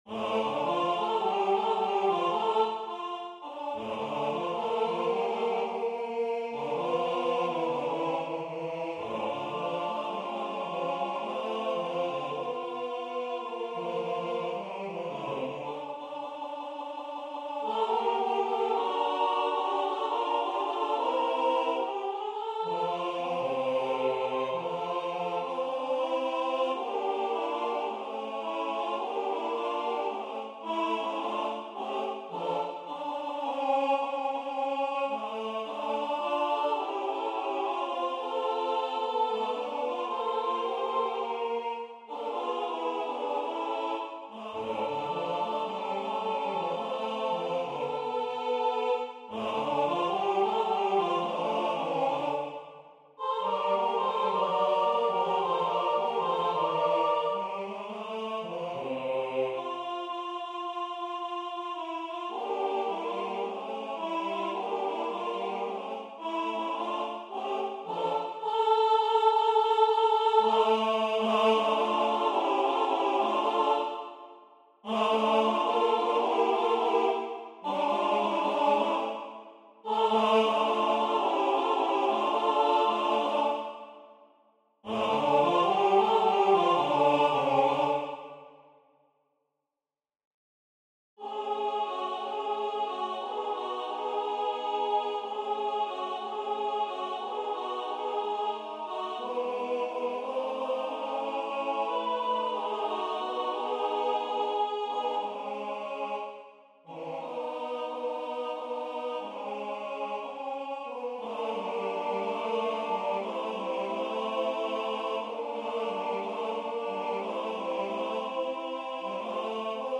Arrangement pour chœur
français Voix : SAT Difficulté